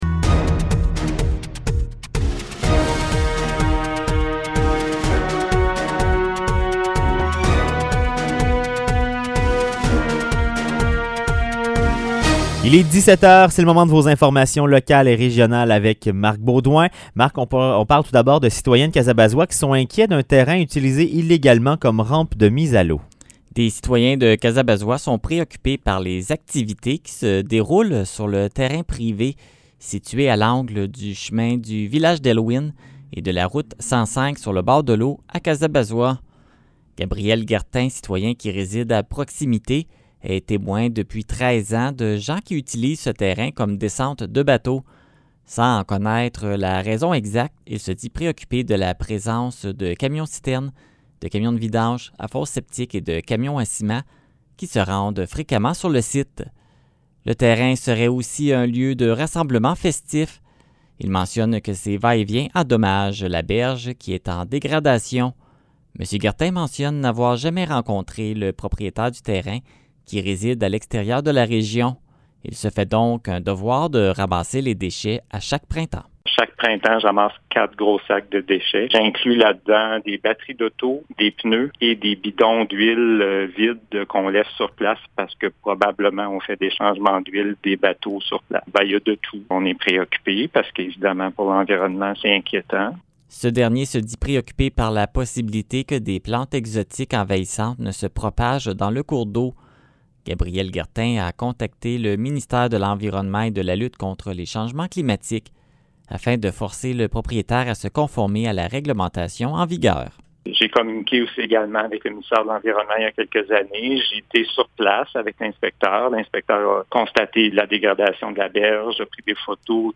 Nouvelles locales - 4 août 2021 - 17 h